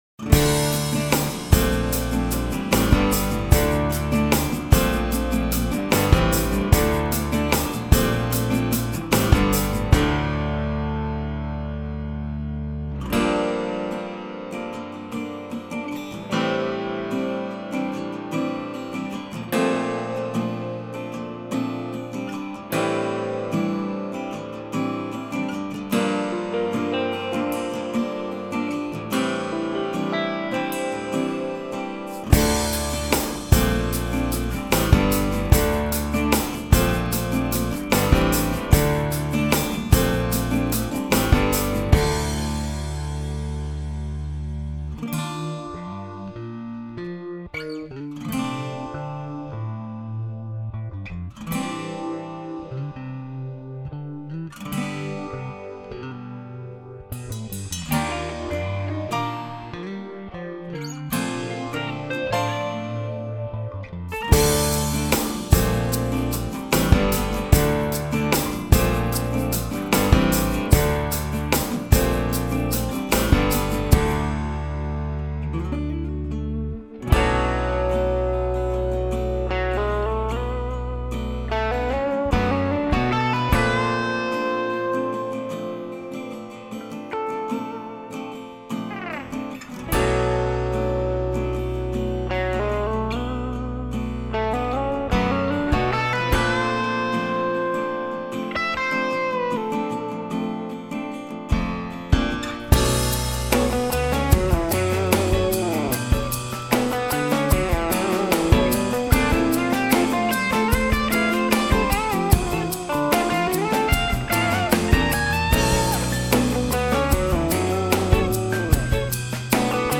Redd preamp, La2a and Pultec signal chain.
Amp volume was around 3, tone about 10.
No EQ, minimal compression, some Ambience reverb.